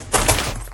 PixelPerfectionCE/assets/minecraft/sounds/mob/horse/jump.ogg at mc116
jump.ogg